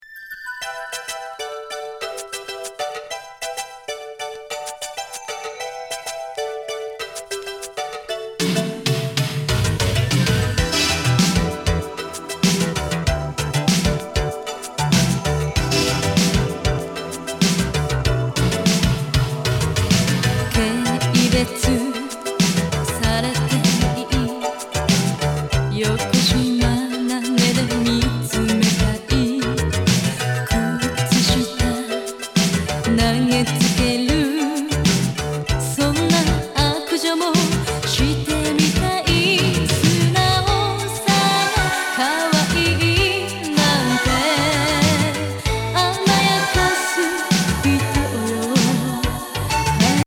アンニュイ・アーバン・シンセ・ディスコ歌謡！